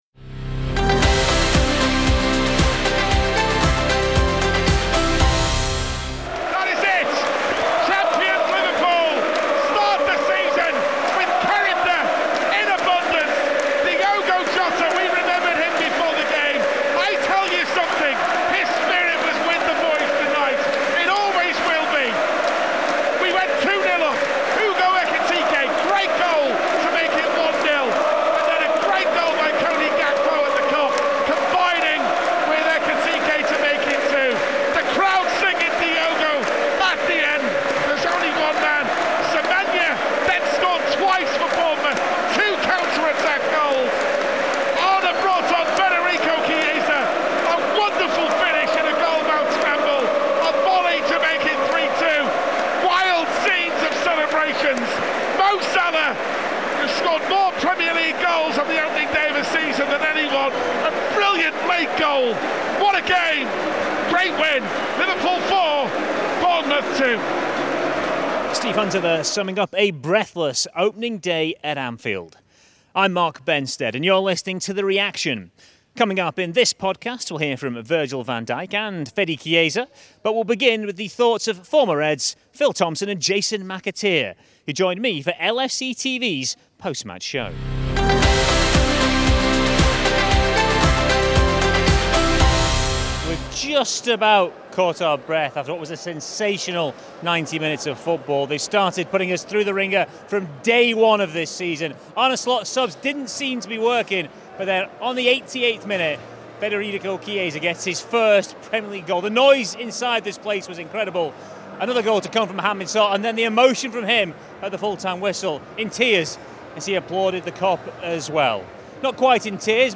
Liverpool began the defence of their Premier League title in dramatic fashion with two late goals to see off the challenge of brave Bournemouth. In the first Reaction podcast of the new season we'll hear from Virgil van Dijk and Federico Chiesa.